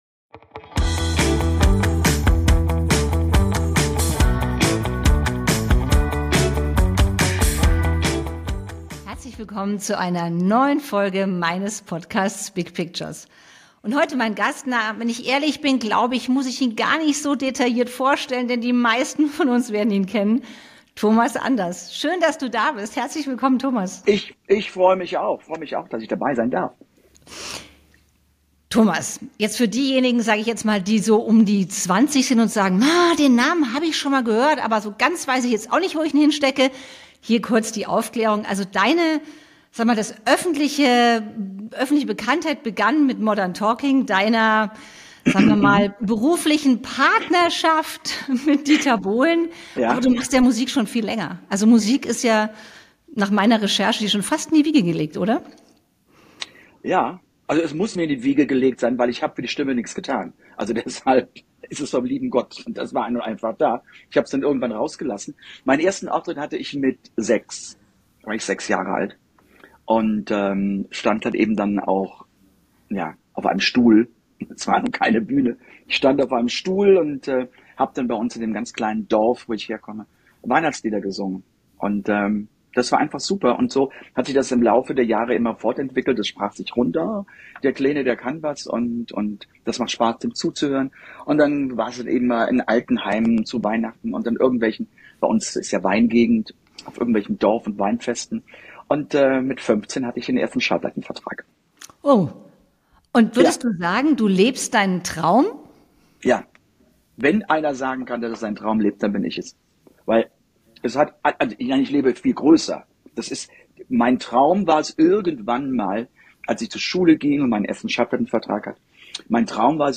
Und hier ist mein Talk, meine Podcastfolge mit Thomas Anders.